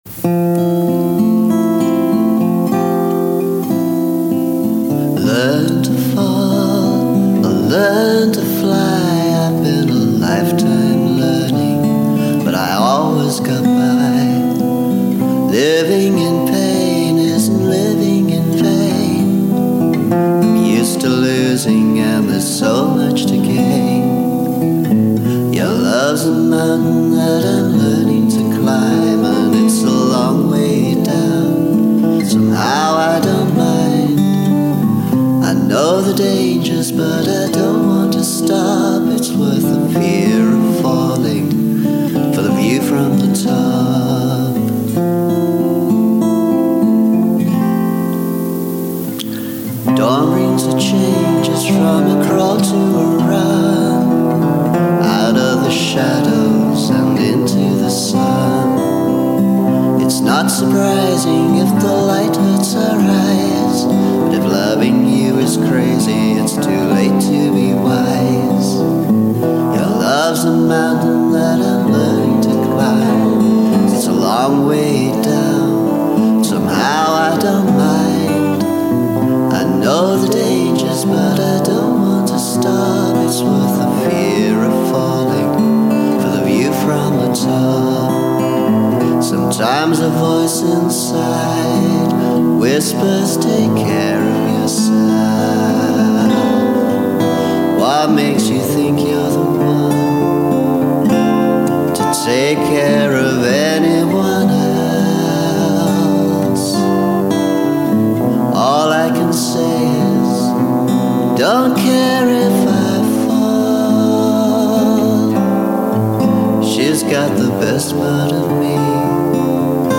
Recorded at CentreSound in the early 1980s.